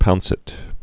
(pounsĭt)